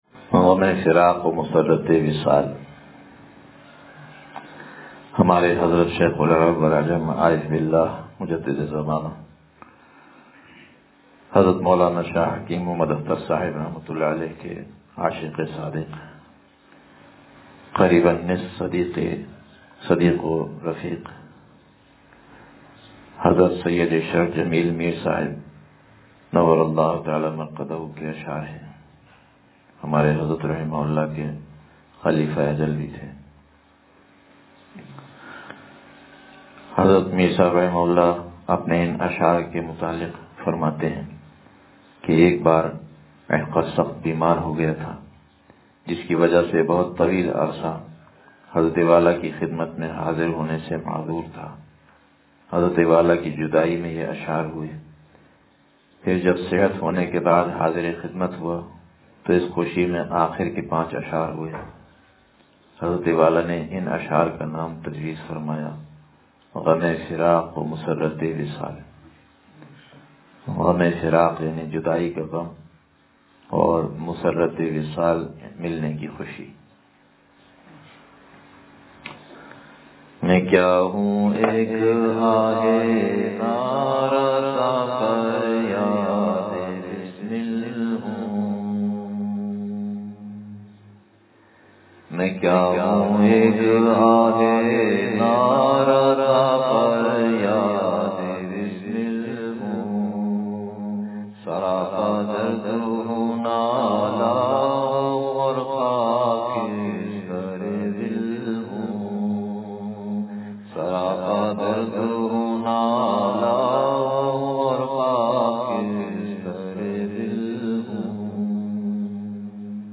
غمِ فراق و مسرتِ وصال – اتوار مجلس